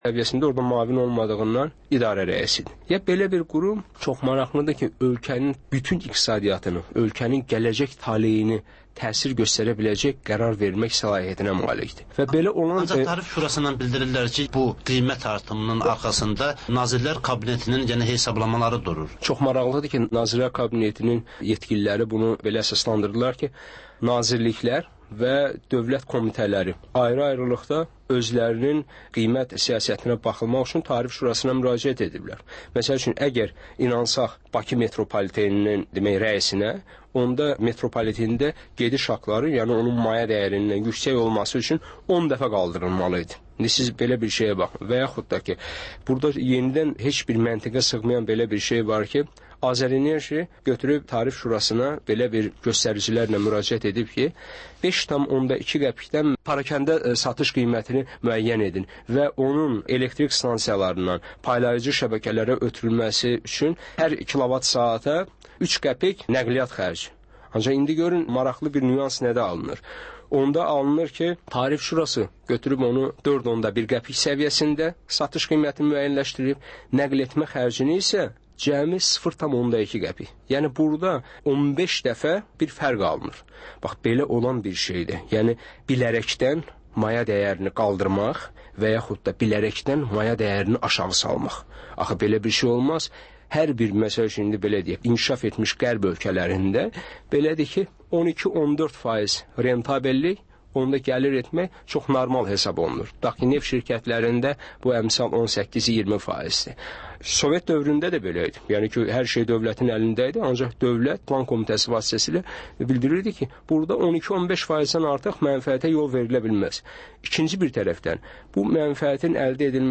Azərbaycan, Gürcüstan ve Ermənistandan reportajlar, müzakirələr